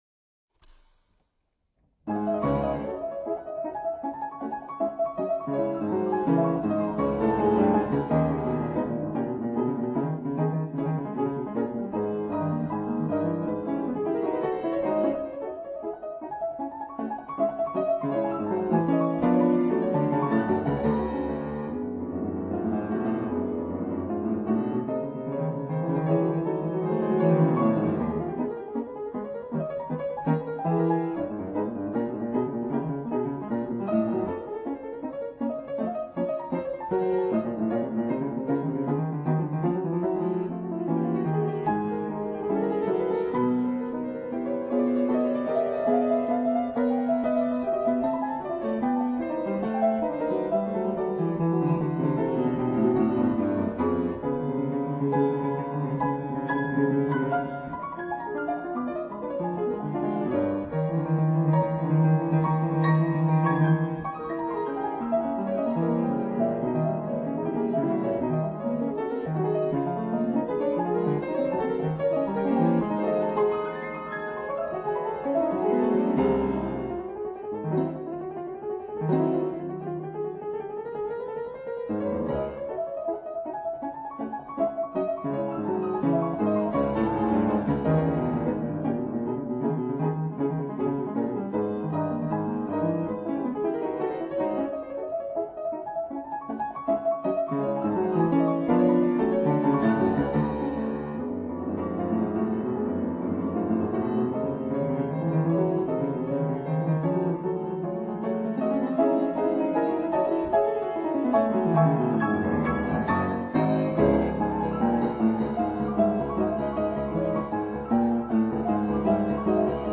(cache) ピアノコンサート１
エチュード Op.10 No.4 2：14 08.07 カッコいい曲ですが、指もつれてますね（汗）